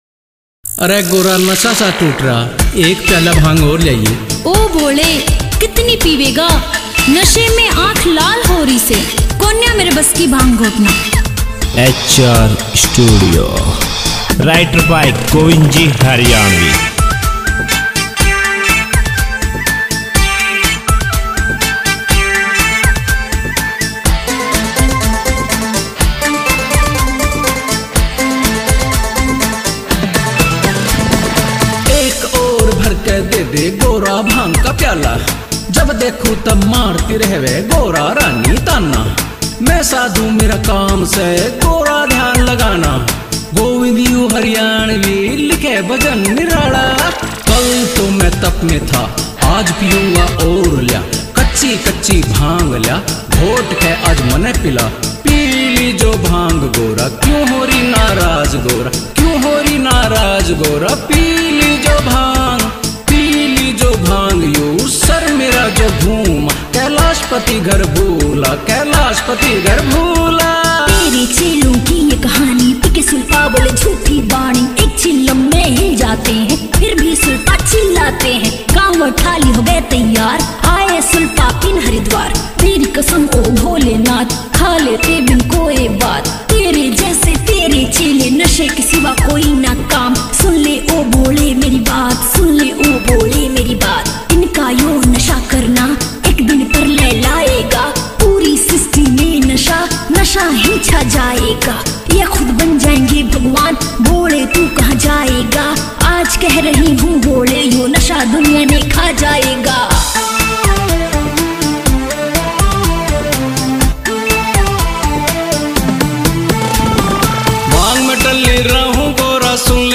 [ Bhakti Songs ]